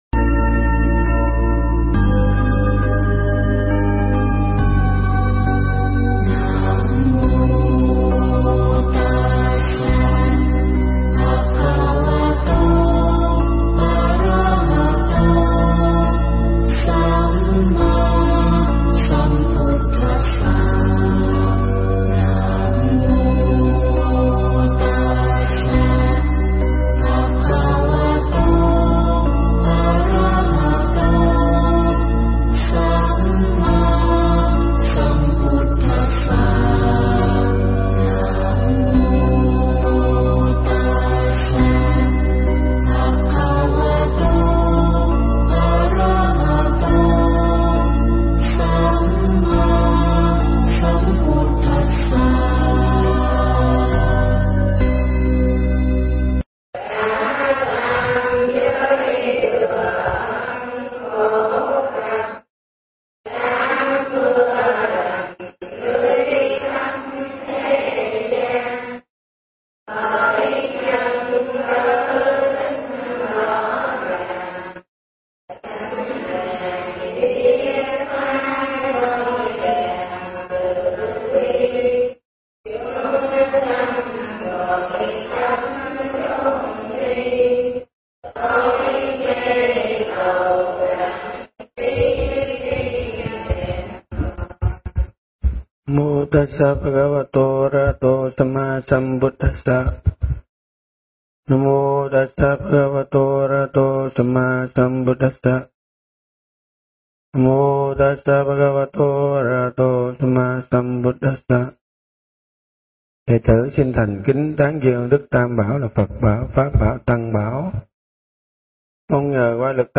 Nghe Mp3 thuyết pháp Tứ Niệm Xứ